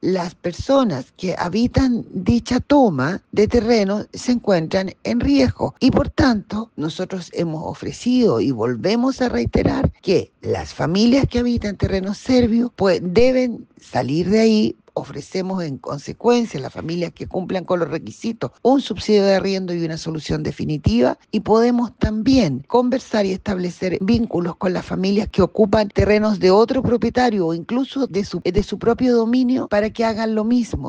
La directora regional del Serviu, María Luz Gajardo, indicó que “las personas que habitan esa toma están en riesgo”.